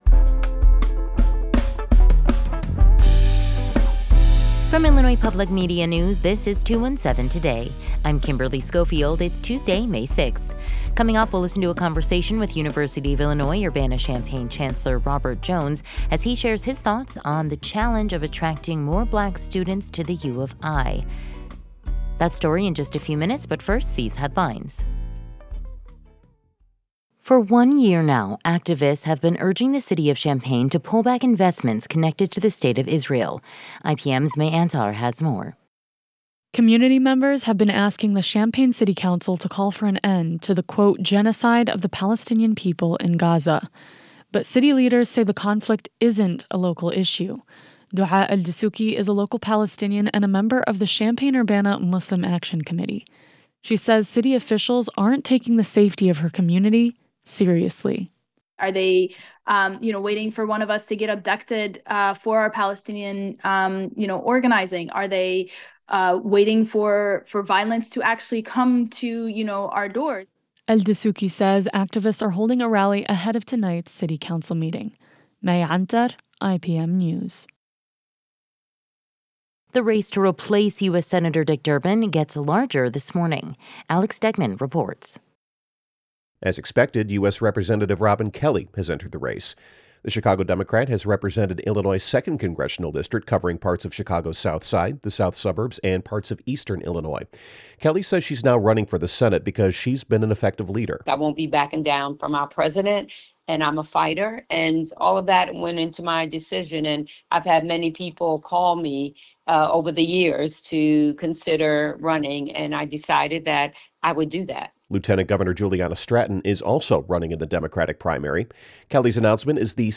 In today's deep dive, we’ll listen to a conversation with University of Illinois Urbana-Champaign Chancellor Robert Jones, who shares his thoughts on the challenge of attracting more Black students to the U of I.